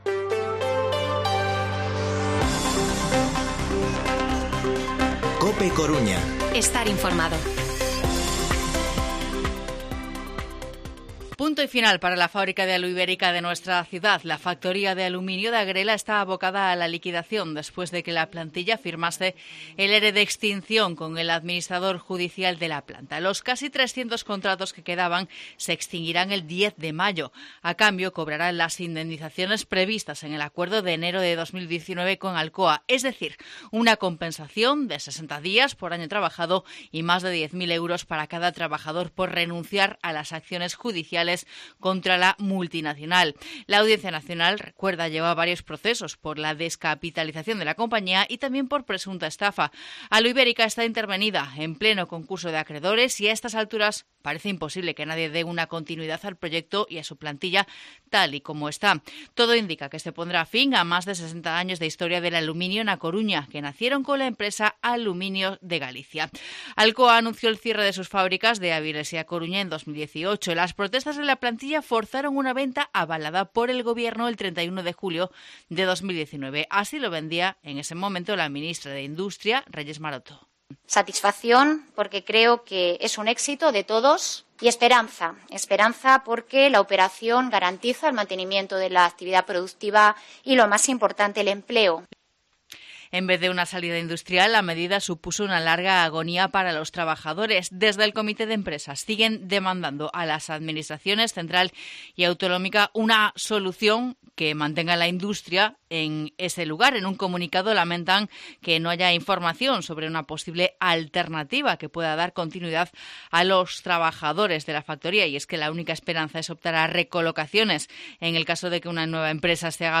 Informativo Mediodía COPE Coruña viernes, 22 de abril de 2022 14:20-14:30